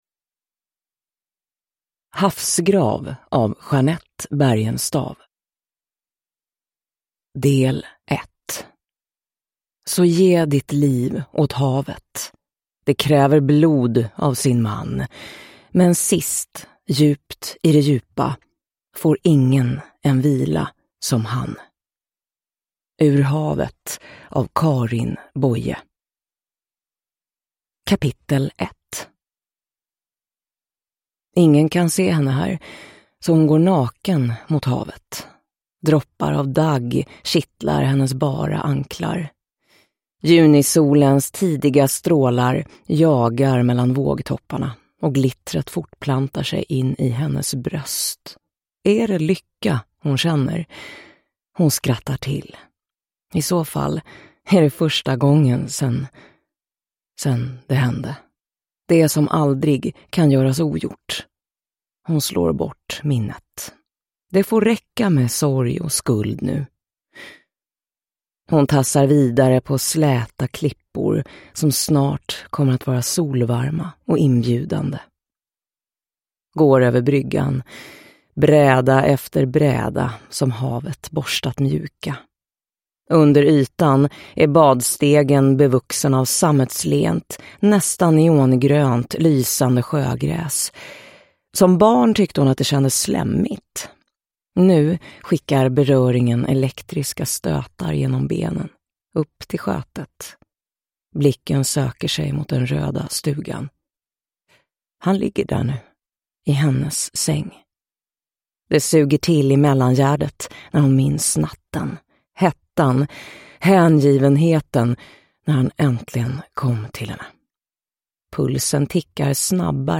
Havsgrav – Ljudbok